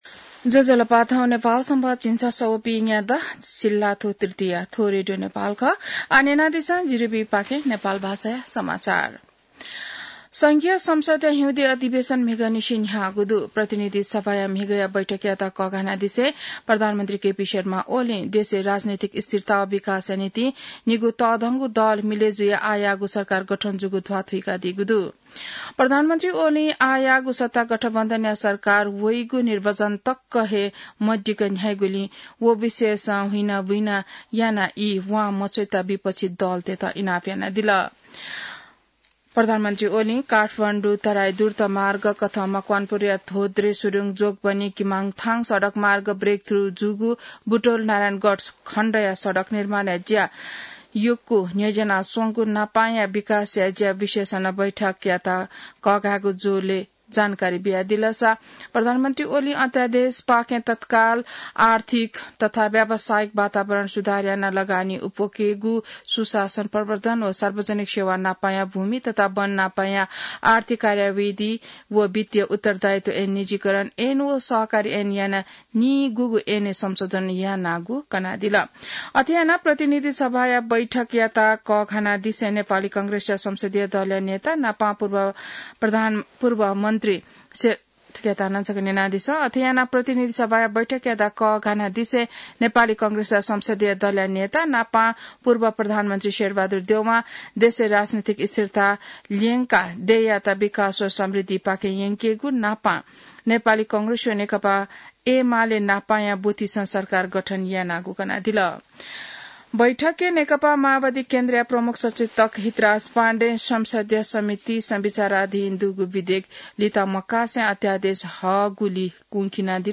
नेपाल भाषामा समाचार : २० माघ , २०८१